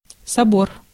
Ääntäminen
IPA : /ˈkaʊn.səl/ US : IPA : [ˈkaʊn.səl]